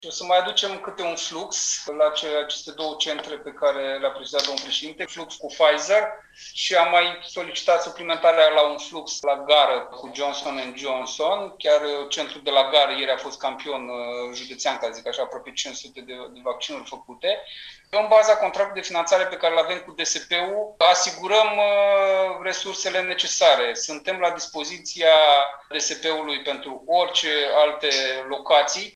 Vicepreședintele Consiliului Județean Iași, Marius Dangă a adăugat că, la centrele de vaccinare de la Palas și Casa Studenților, va fi administrat serul produs de Pfizer, iar la Gară, serul Johnsson&Johnsson: